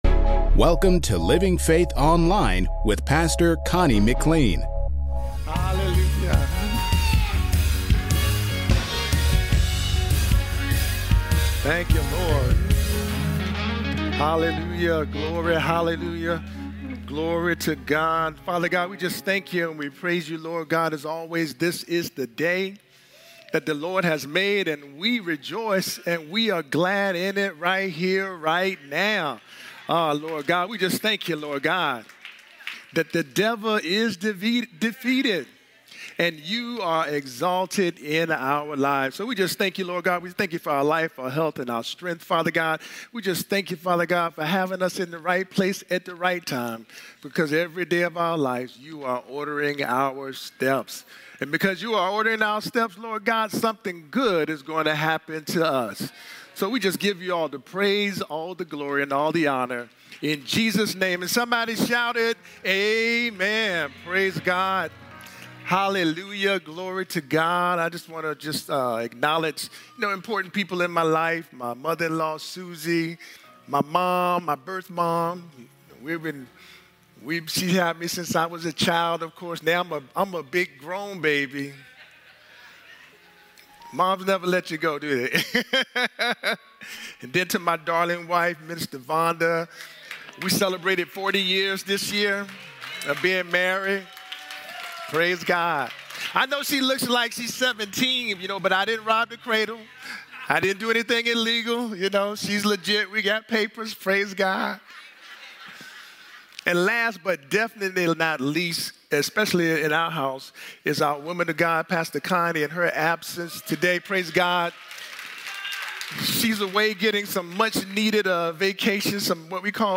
Sermons | Living Faith Christian Center